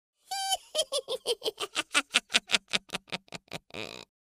Witch Cackle Sound
horror
Witch Cackle